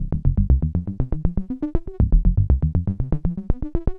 Code Red (Bass) 120BPM.wav